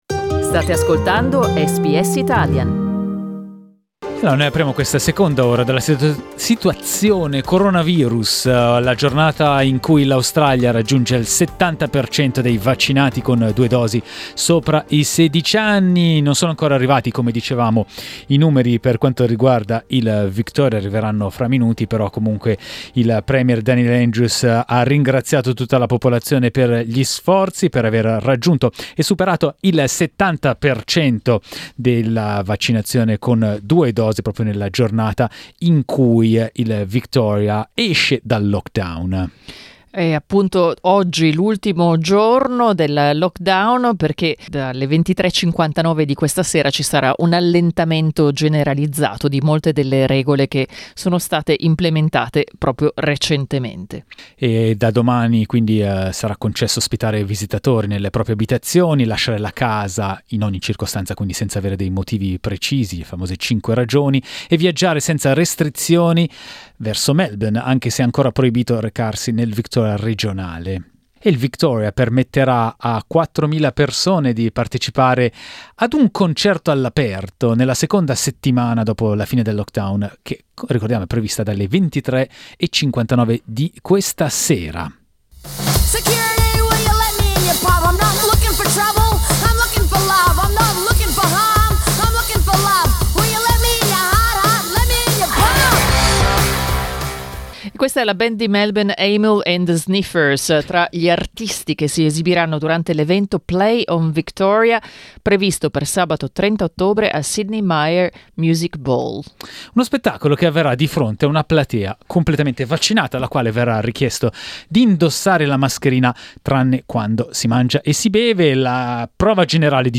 Ascolta il nostro aggiornamento in diretta di giovedì 21 ottobre sulla campagna vaccinale in Australia e le percentuali raggiunte da stati e territori.